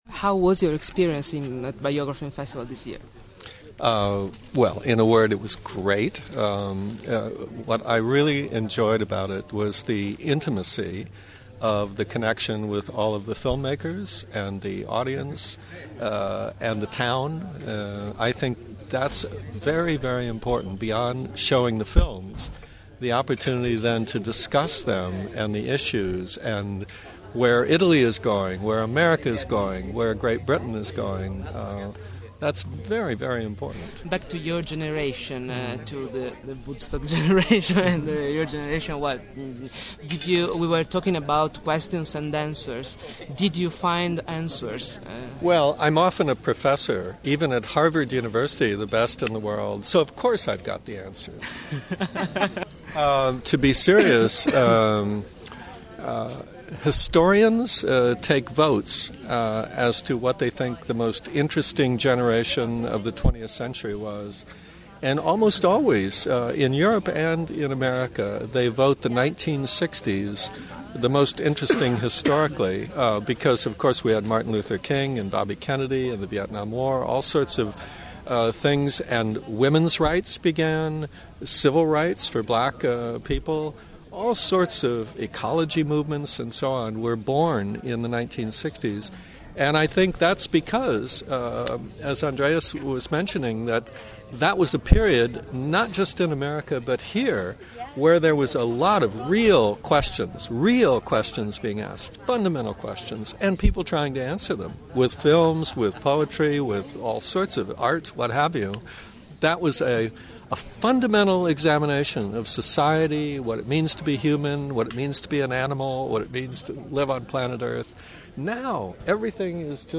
Intervista_wadleigh_en.mp3